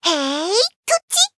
文件 文件历史 文件用途 全域文件用途 Chorong_skill_02.ogg （Ogg Vorbis声音文件，长度1.4秒，111 kbps，文件大小：19 KB） 源地址:地下城与勇士游戏语音 文件历史 点击某个日期/时间查看对应时刻的文件。